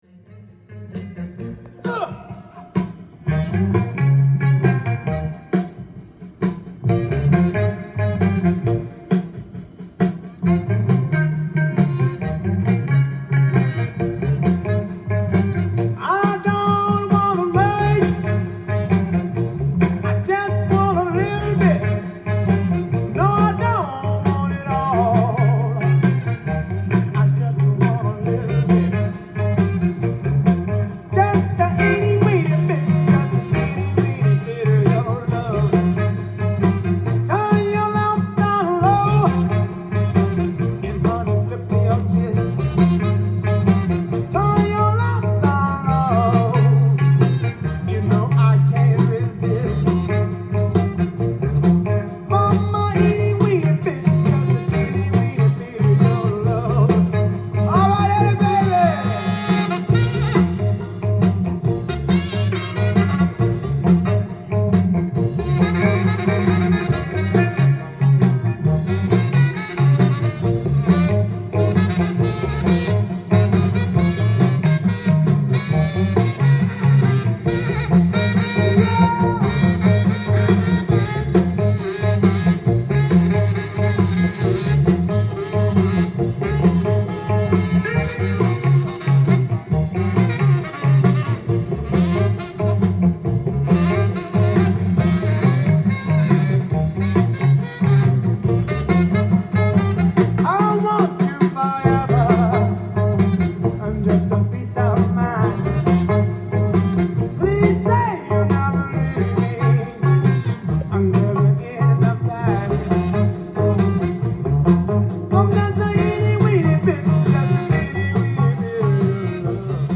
店頭で録音した音源の為、多少の外部音や音質の悪さはございますが、サンプルとしてご視聴ください。
SAXも入るご機嫌なファンキー・ブルース
伸びのあるエモーショナルなヴォーカルとキレのあるギターを聴かせてくれる。シカゴ・モダン・ブルース傑作。